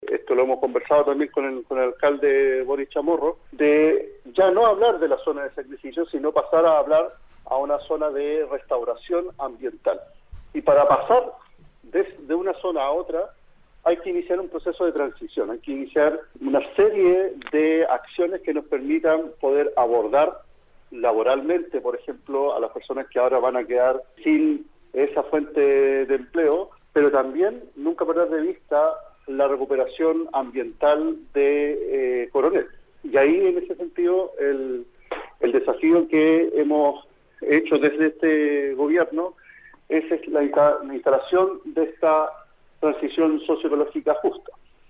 Al respecto, Óscar Reicher Salazar, seremi de Medio Ambiente, explicó la relevancia de la paralización de la planta de Enel.